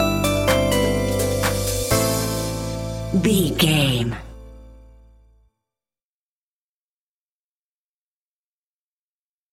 Aeolian/Minor
groovy
uplifting
futuristic
drum machine
synthesiser
bass guitar
funky house
upbeat